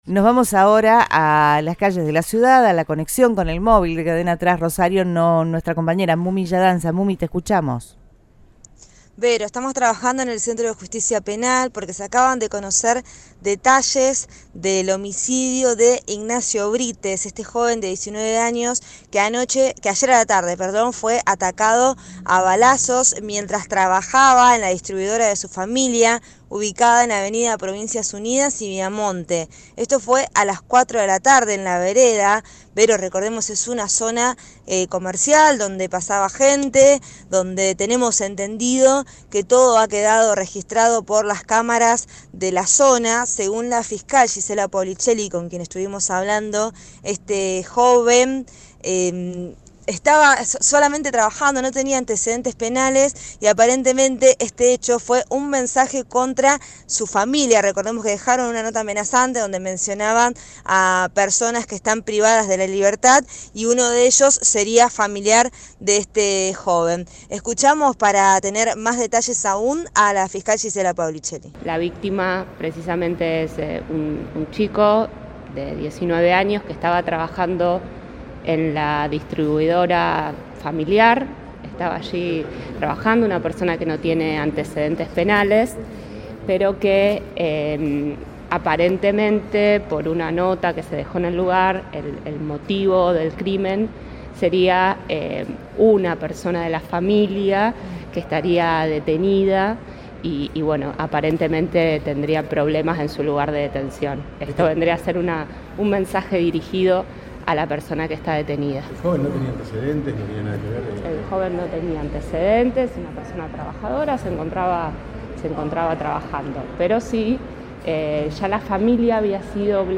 Gisella Paolicelli brindó una conferencia de prensa